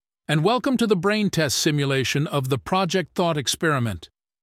anouncer_intro_02.ogg